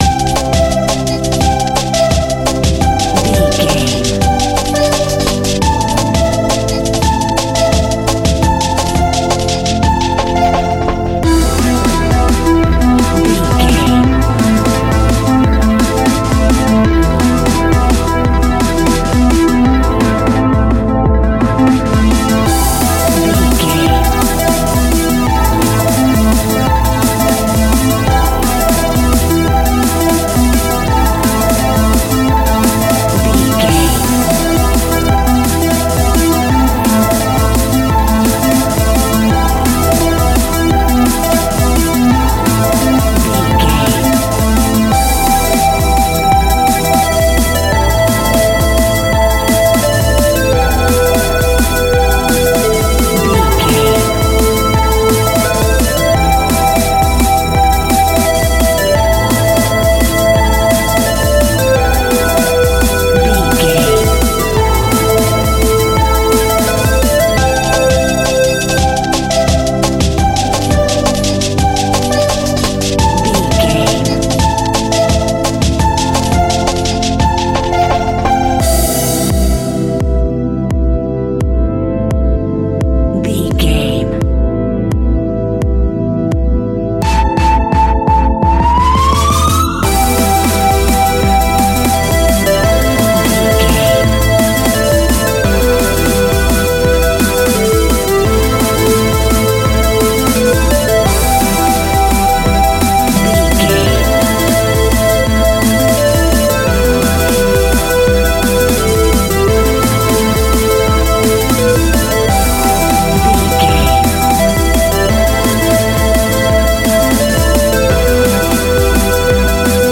Ionian/Major
groovy
high tech
uplifting
futuristic
driving
energetic
repetitive
drums
synthesiser
drum machine
electronic
sub bass
synth leads
synth bass